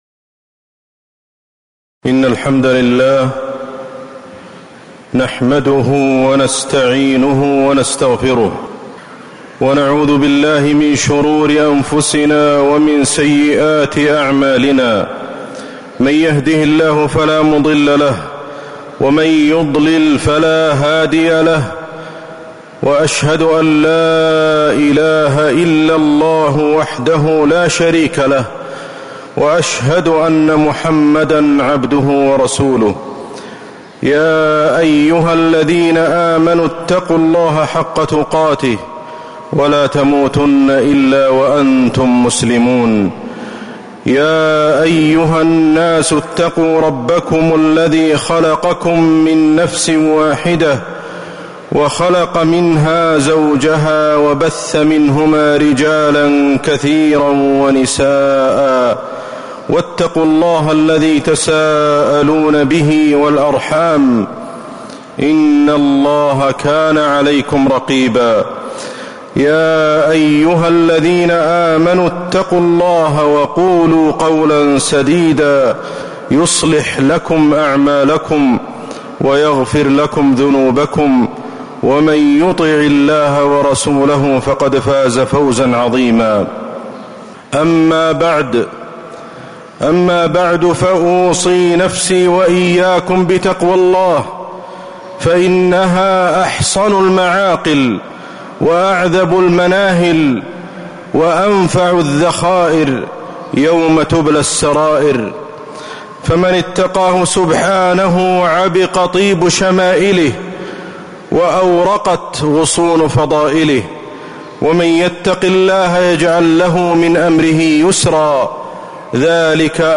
خطبة الإيمان والحياة وفيها: سنة الحياة الدنيا في المضي، والتشبيه النبوي لحال المؤمن مع الدنيا، الإيمان وأثره على الحياة
تاريخ النشر ٢٦ صفر ١٤٤٦ المكان: المسجد النبوي الشيخ: فضيلة الشيخ أحمد الحذيفي فضيلة الشيخ أحمد الحذيفي الإيمان والحياة